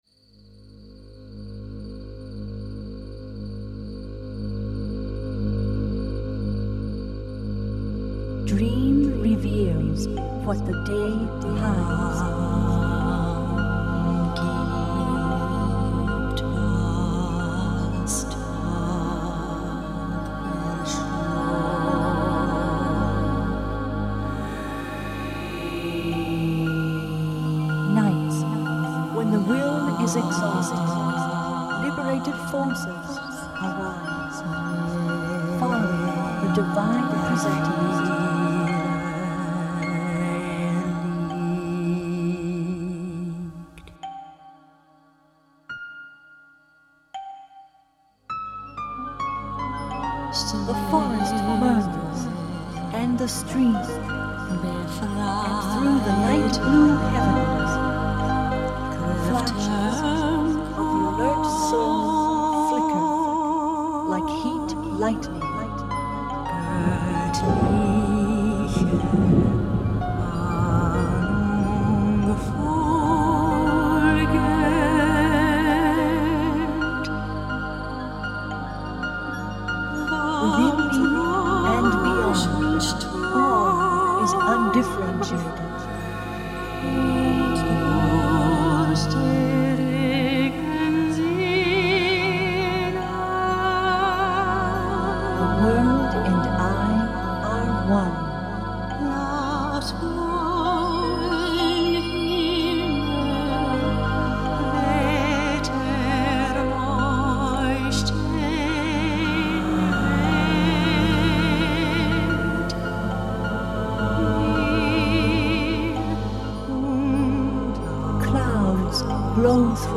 For Radio Baton & Voice
Bohlen-Pierce Scale Music and Videos